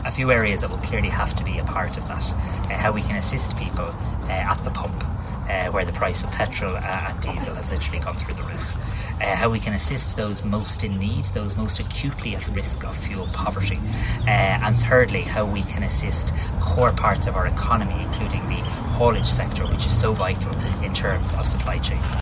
Speaking in Galway today, the Tánaiste says there are three key areas to be addressed………..